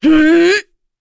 Morrah-Vox_Casting1.wav